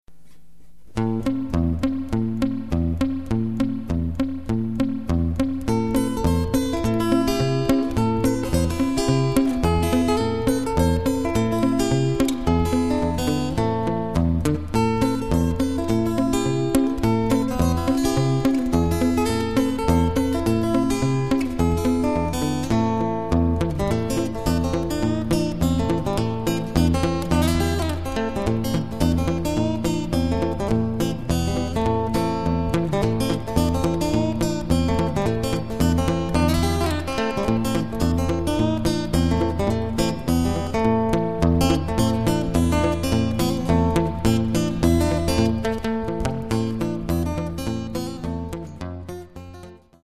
A great moment of  Picking style music.